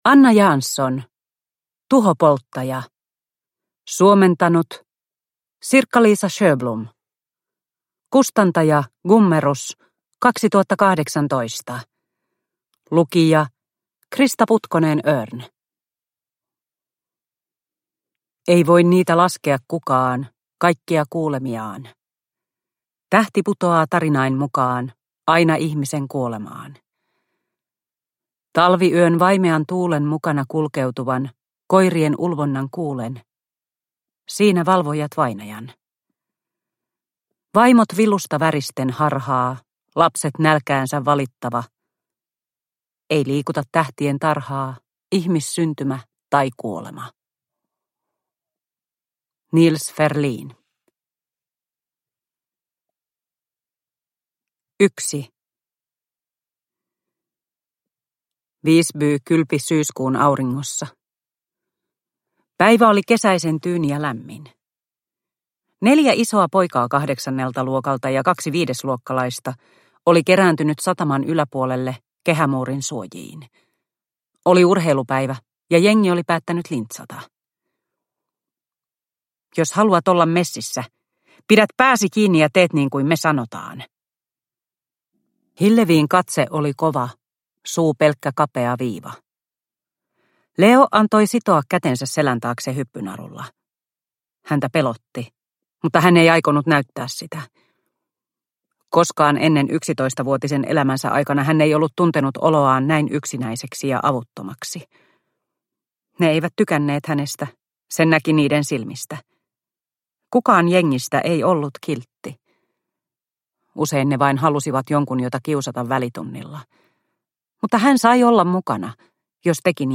Tuhopolttaja – Ljudbok – Laddas ner